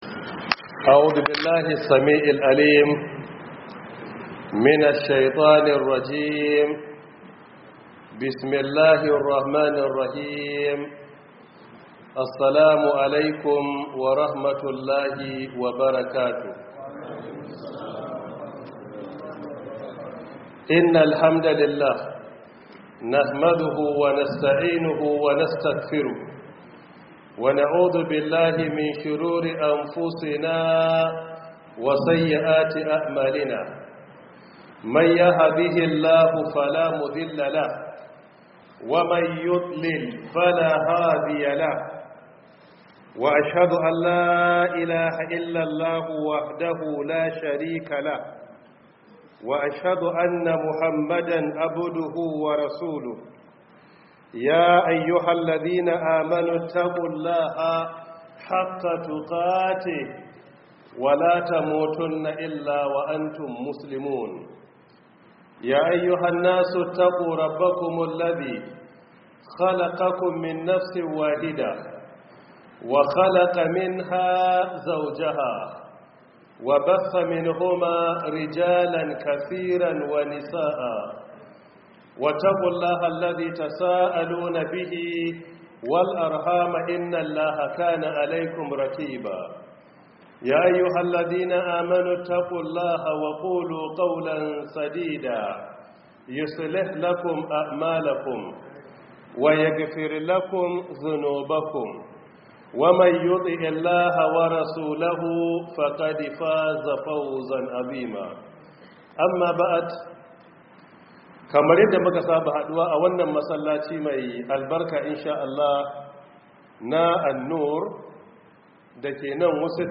019 Prof Isa Ali Pantami Tafsir 2026